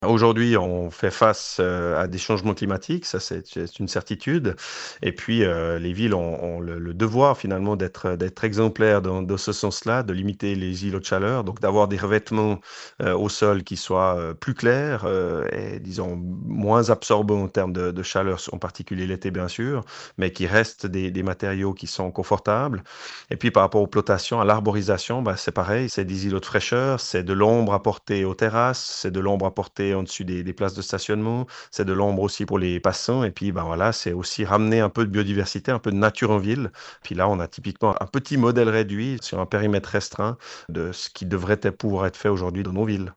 Selon Grégory Devaud, syndic d’Aigle, il est désormais essentiel d’intégrer des surfaces vertes au sein de ces nouveaux projets.
On retrouve Gregory Devaud, syndic d’Aigle.